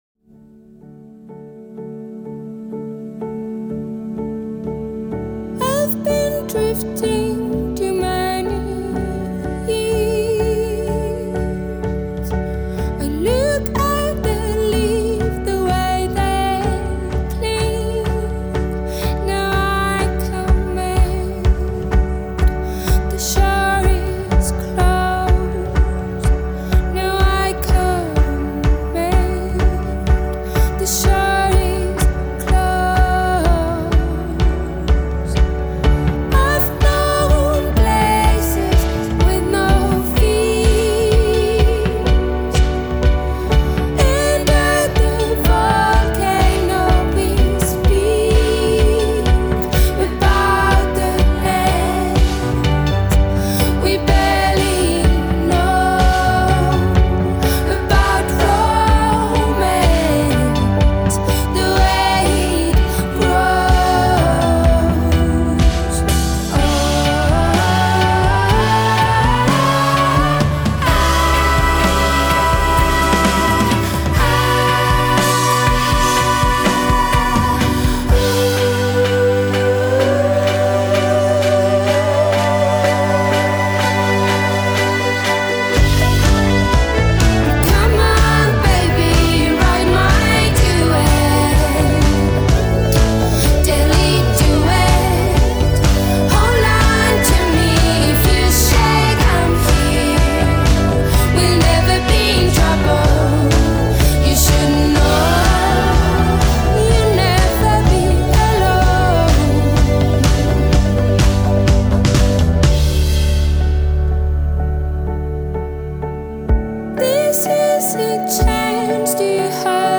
baroquely-crafted take on pop music
pulsating, eloquent
voice is filled with a resonating, cracking tension
leading up to the soaring chorus